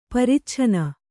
♪ paricchanna